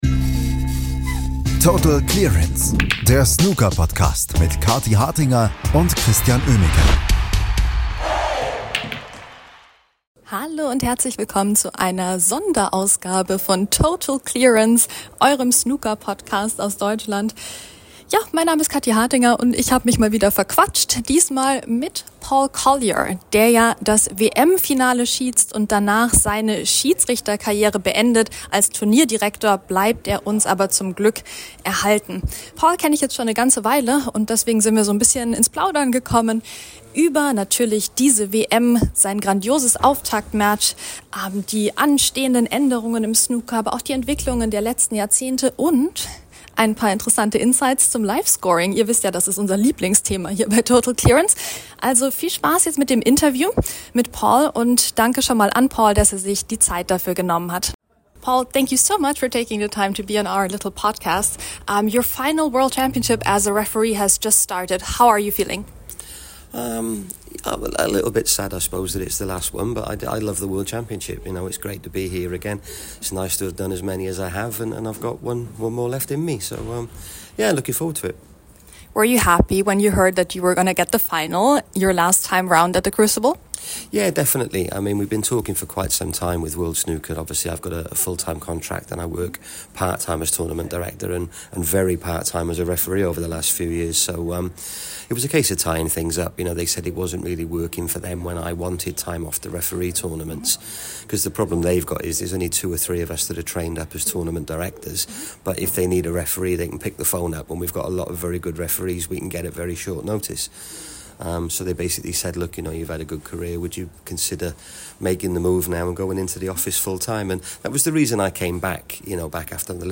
Paul Collier bei seiner letzten WM im Interview ~ Snooker Podcast